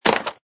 stone2.mp3